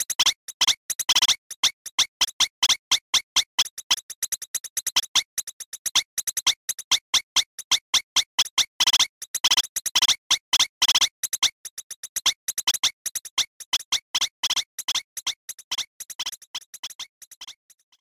Voice clip from Tetris & Dr. Mario